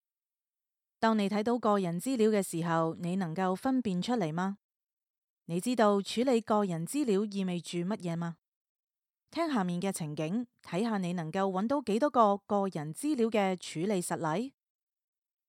Female
My voice is warm, friendly, clear, gentle, enthusiastic, firm, and sweet, with the versatility to perform across e‑learning, narration, commercial work, character voice acting, and more.
E-Learning